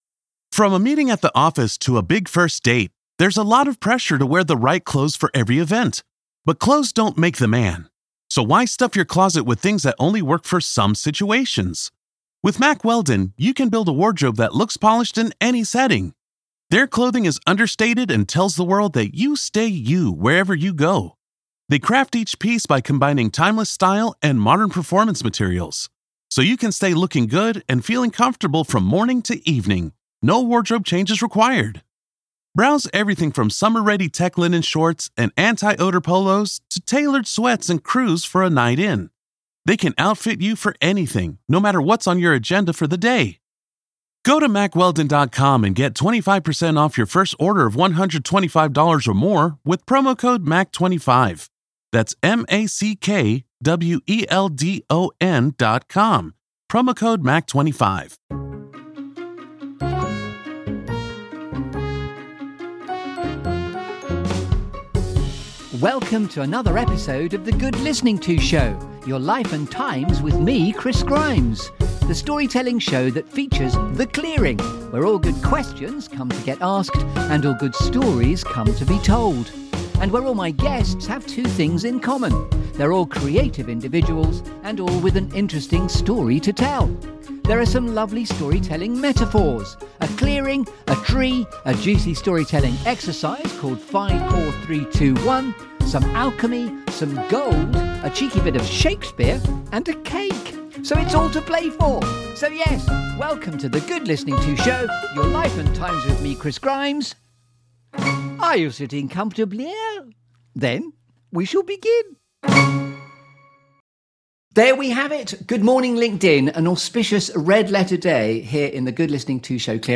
This feel-good Storytelling Show that brings you ‘The Clearing’.
Think Stories rather than Music!